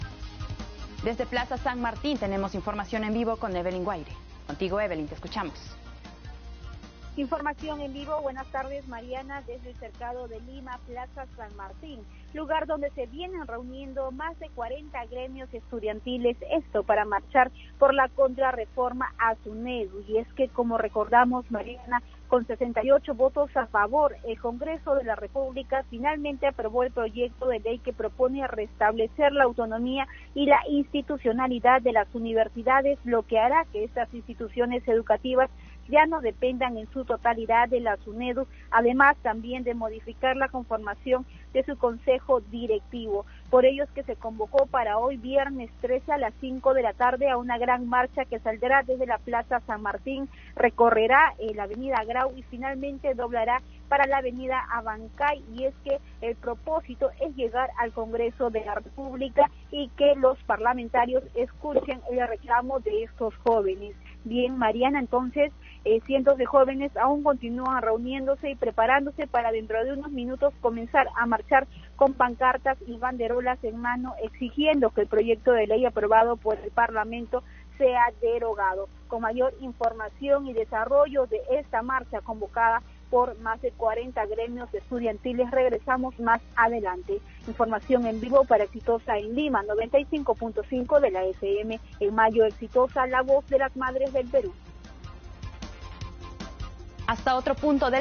Microinformativo - Exitosa Tv
En vivo desde la Plaza San Martín en Cercado de Lima, informan que más de 40 gremios estudiantiles inician una marcha en protesta contra la contrarreforma a la SUNEDU aprobada por el Congreso.